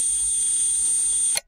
Servo Motor
A precision servo motor whirring and positioning with electronic hum and mechanical stop
servo-motor.mp3